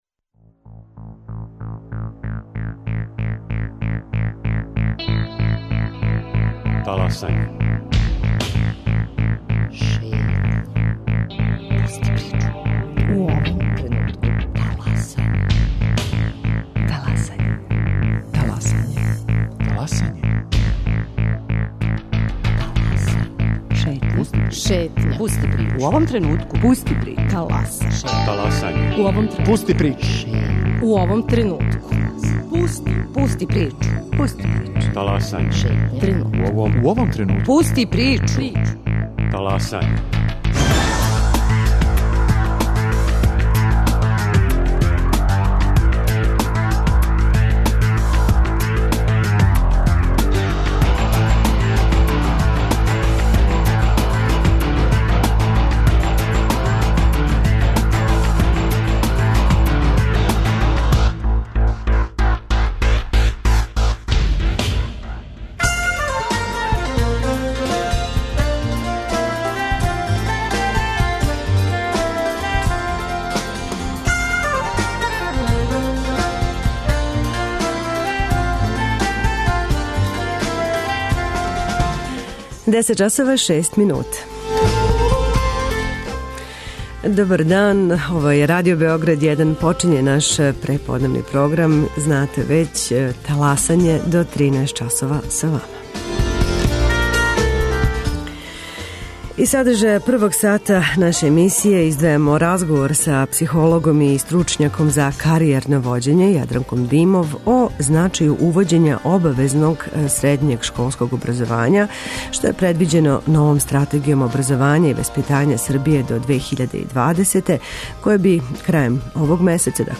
Из садржаја првог сата емисије издвајамо разговор